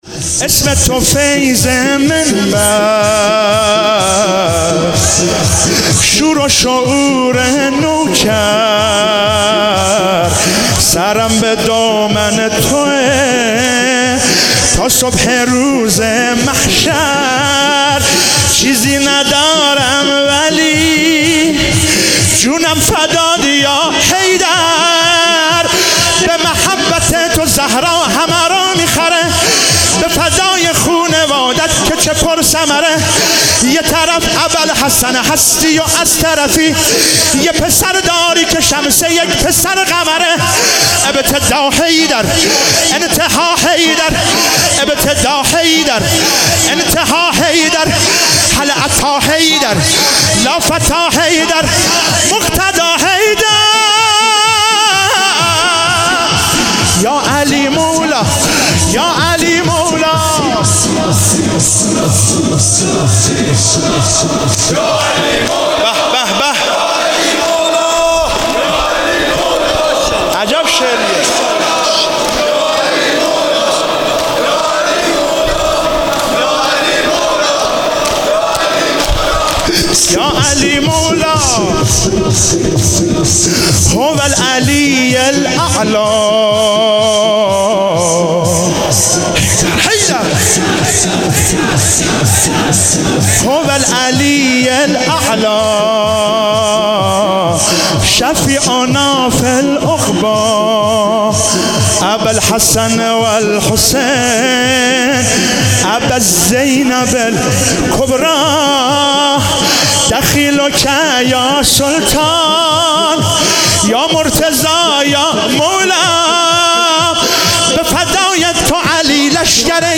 شب 21 رمضان 97 - شور - اسم تو فیض منبر شور و شعور نوکر
ماه رمضان